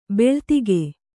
♪ beḷtige